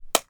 household
Whole Egg Hits Ground 2